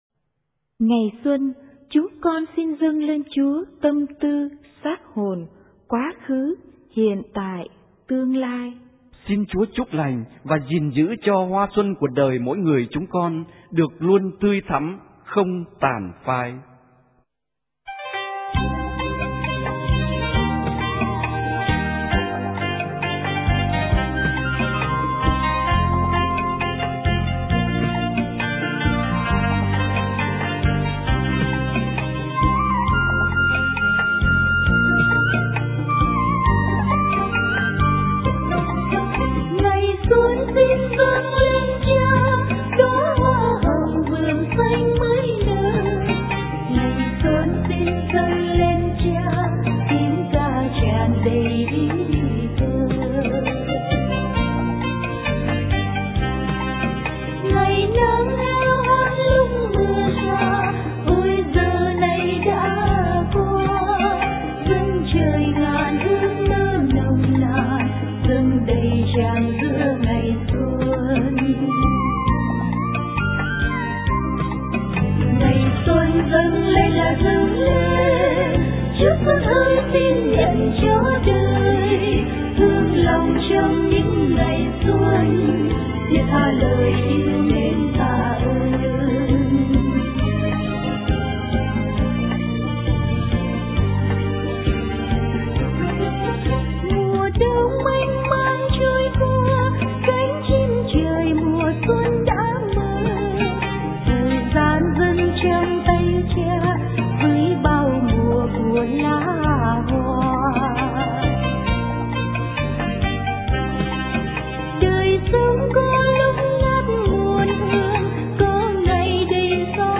* Thể loại: Mừng Xuân